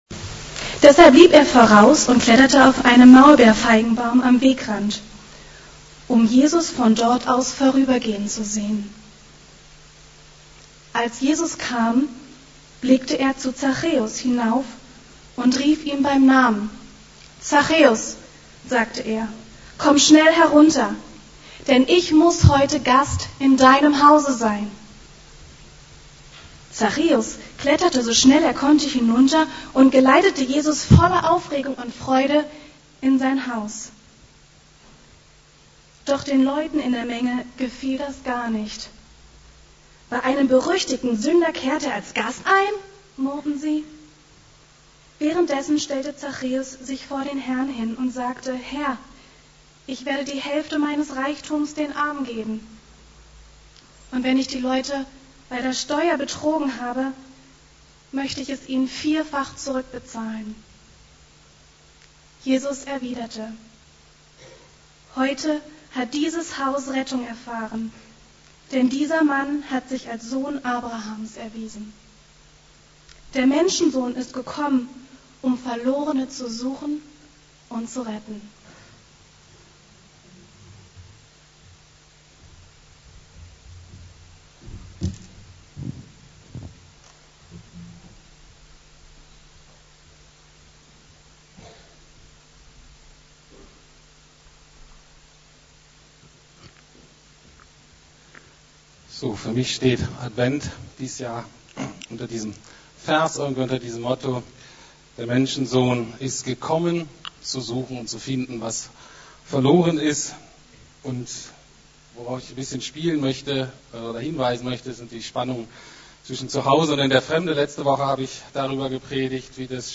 Advent- zu Hause in der Fremde ~ Predigten der LUKAS GEMEINDE Podcast